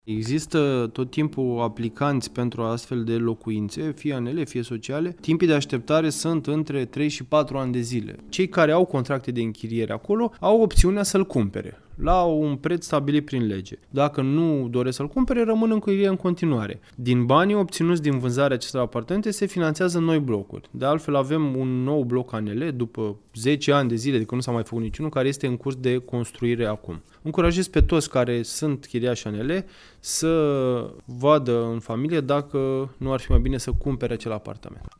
Primarul  Lucian Stanciu-Viziteu a subliniat că documentațiile cadastrale sunt deja în curs de elaborare.